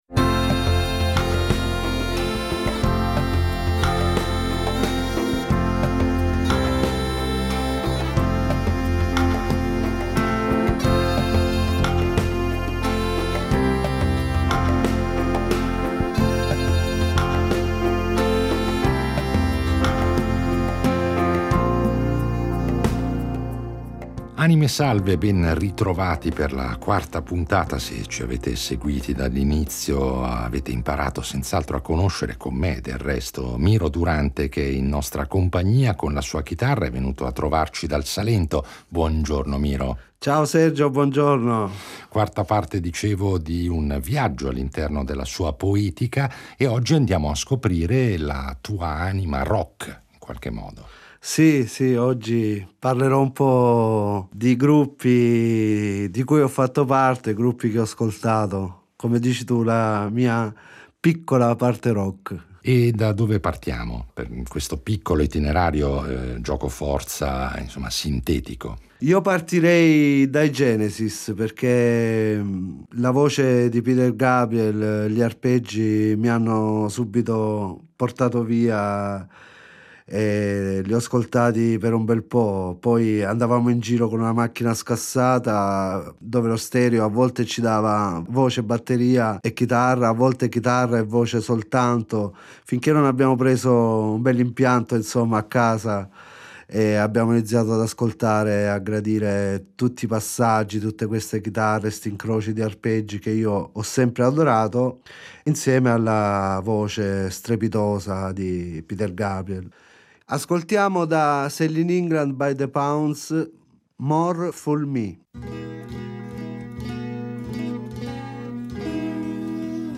con la sua chitarra, cantando e spiegando al nostro pubblico i suoi testi in dialetto salentino.